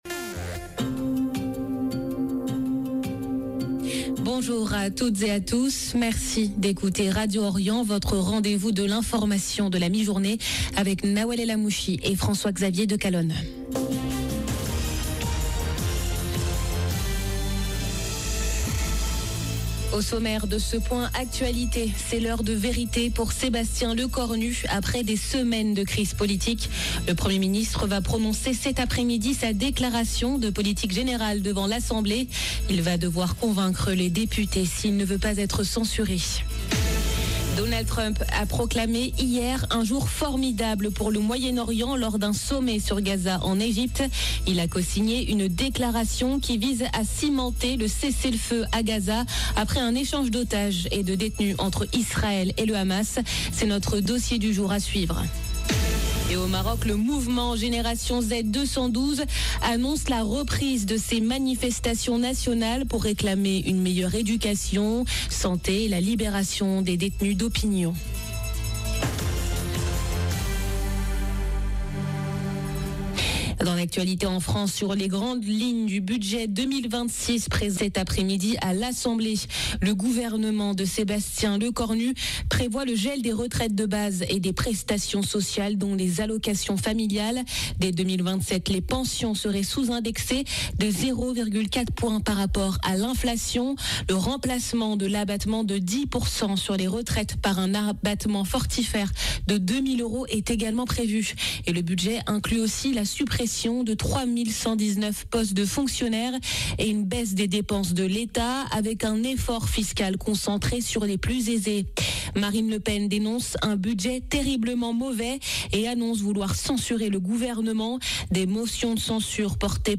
Journal de midi du 14 octobre 2025